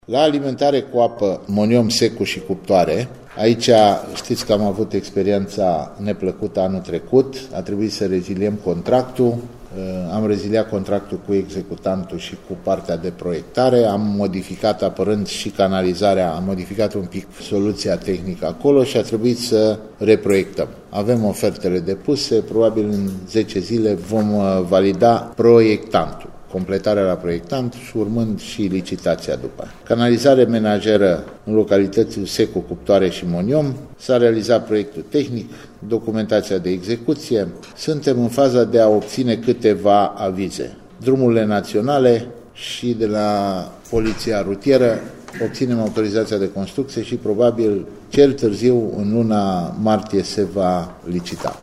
În ceea ce priveşte alimentarea cu apă a cartierelor Moniom, Secu şi Cuptoare au fost reziliate contractele şi se analizează alte oferte, spune viceprimarul Ioan Crina.